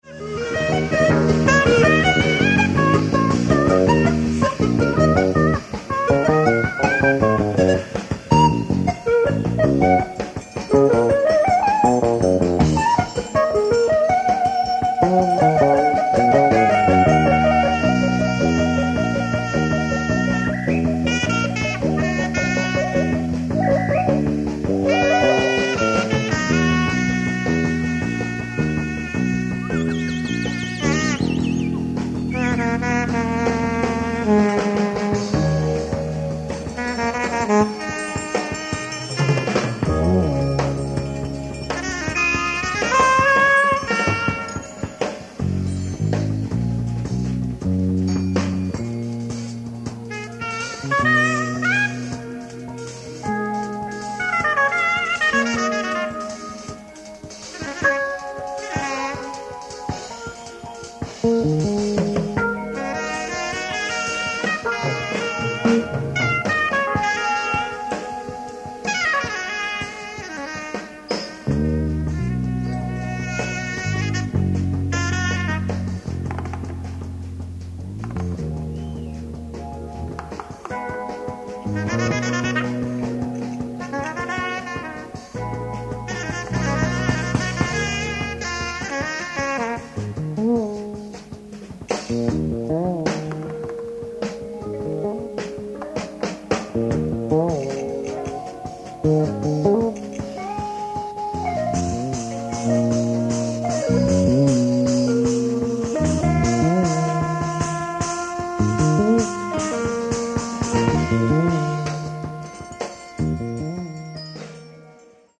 ライブ・アット・ザ・アゴラ、クリーブランド、オハイオ 08/22/1975
リマスター処理を施したサウンドボード音源音源を収録！
※試聴用に実際より音質を落としています。